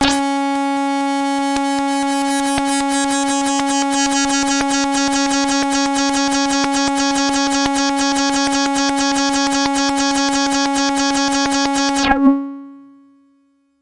描述：通过Modular Sample从模拟合成器采样的单音。
Tag: CSharp5 MIDI音符-73 DSI-利 合成器 单票据 多重采样